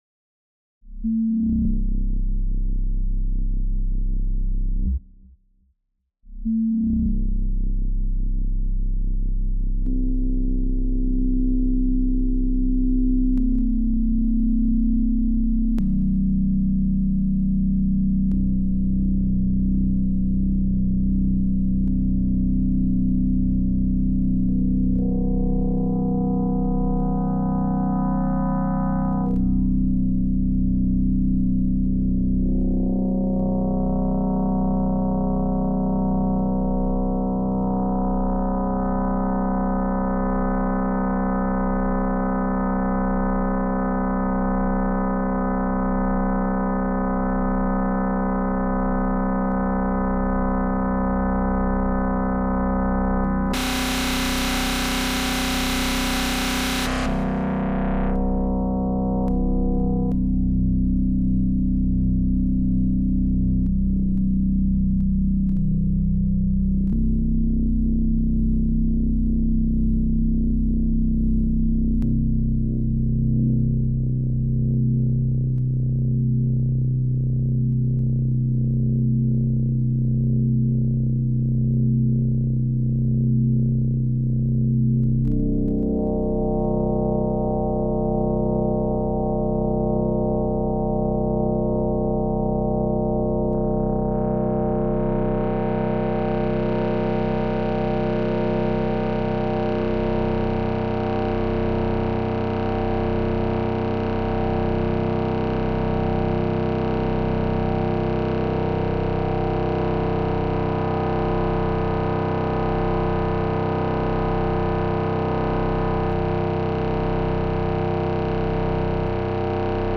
M4L synth test 2- bass